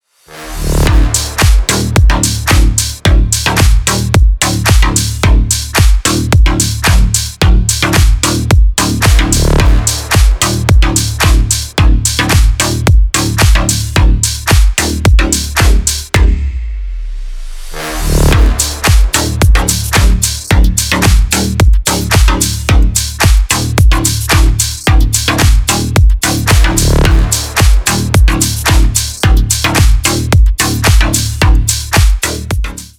Громкие рингтоны / Клубные рингтоны